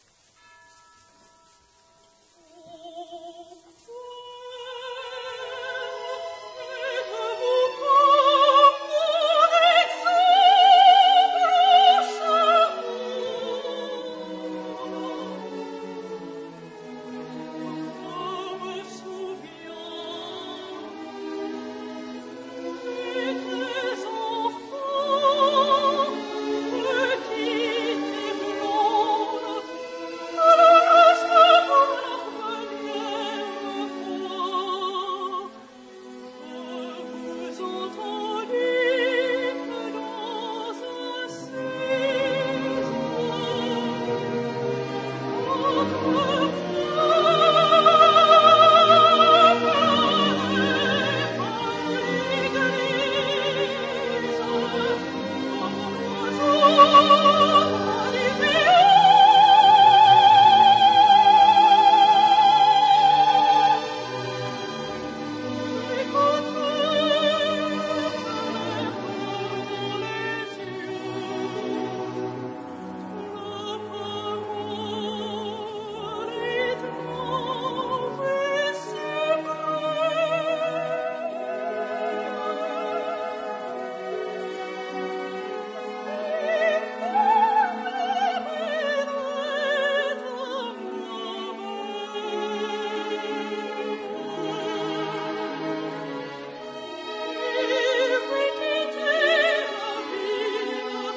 這兩套曲目都是很好的範例，層次相當清楚，動態幾乎不壓縮。
可以很清楚地區分不同樂器所在、與其質感。